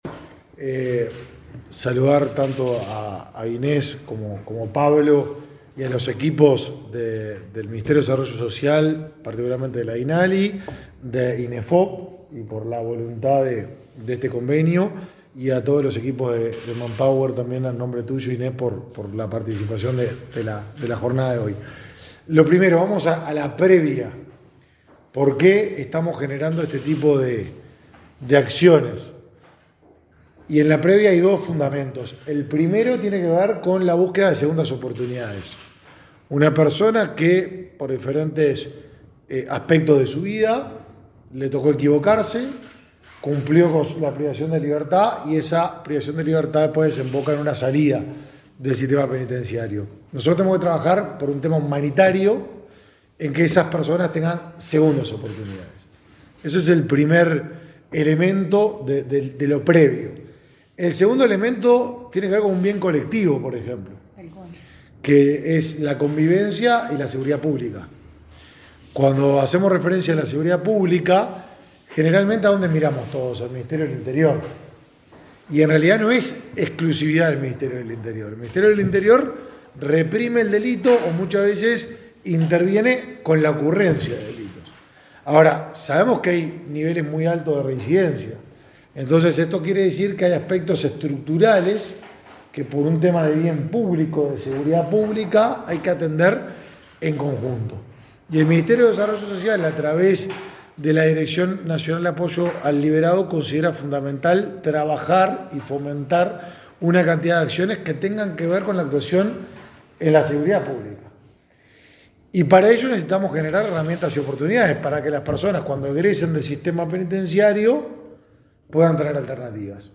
Palabras de autoridades en firma de convenio entre Dinali, Inefop y Manpower
Palabras de autoridades en firma de convenio entre Dinali, Inefop y Manpower 26/07/2023 Compartir Facebook X Copiar enlace WhatsApp LinkedIn La Dirección Nacional del Liberado (Dinali), el Instituto Nacional de Empleo y Formación Profestional (Inefop) y la empresa Manpower suscribieron un convenio para implementar un plan piloto, denominado Derribando Barreras. El ministro de Desarrollo Social, Martín Lema, y el director del Inefop, Pablo Darscht, señalaron la importancia del acuerdo.